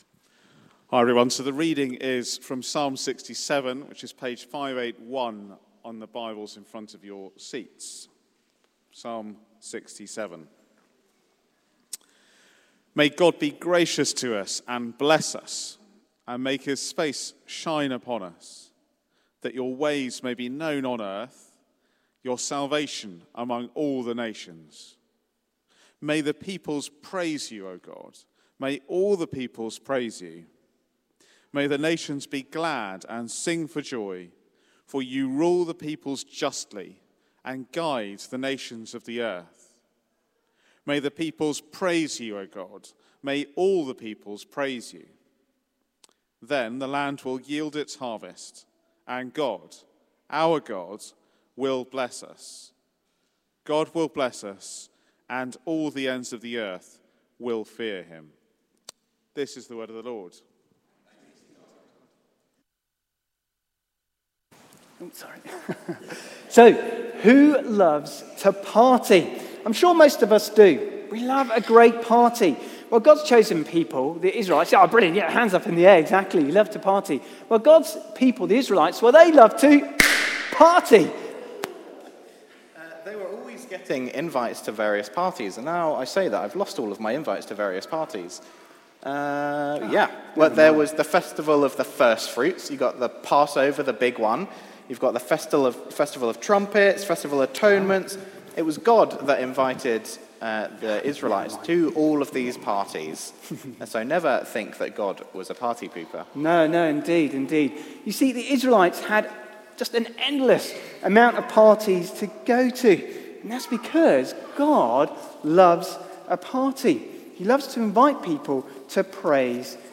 Media for Service (10.45)